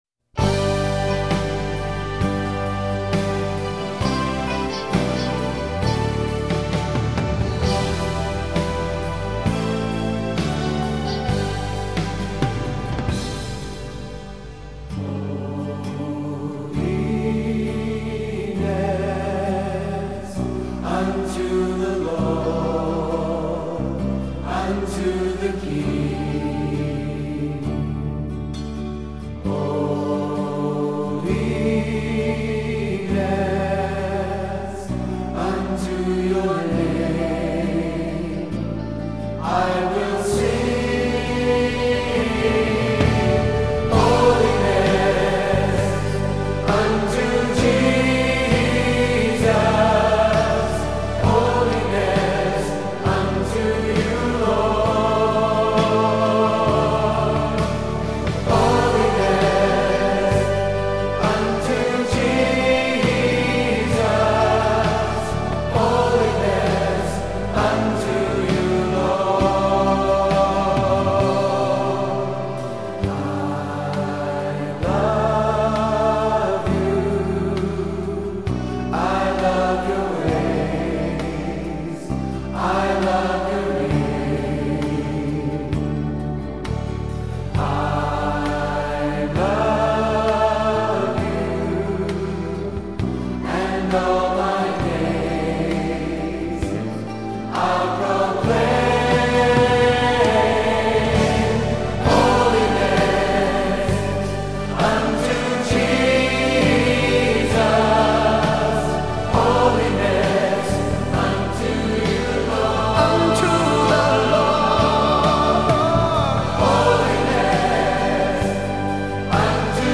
Worship, Music
Majestic and powerful this song is, yes, hmm.